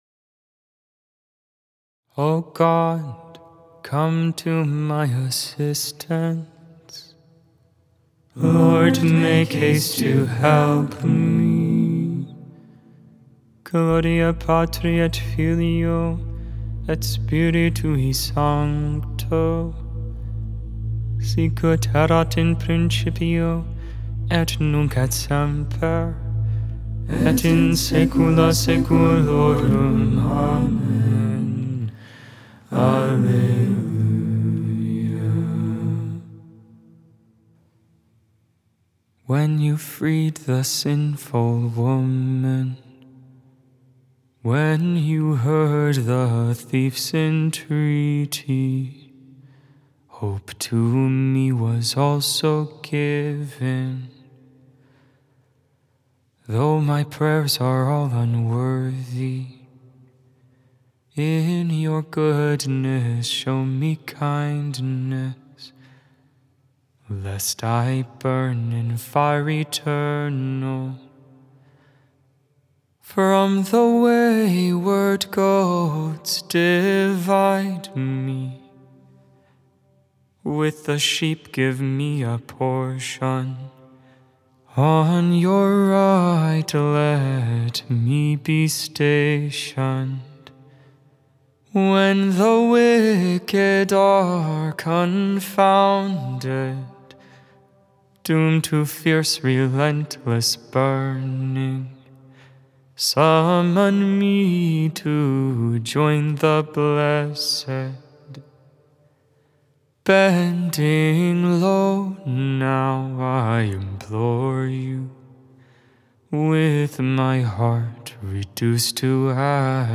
Magnificat (tone VIII)